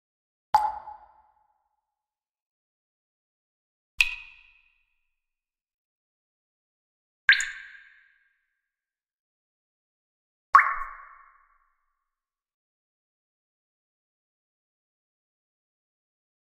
دانلود آهنگ چکه چکه قطره آب 3 از افکت صوتی طبیعت و محیط
دانلود صدای چکه چکه قطره آب 3 از ساعد نیوز با لینک مستقیم و کیفیت بالا
جلوه های صوتی